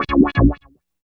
84 WAH-WAH-L.wav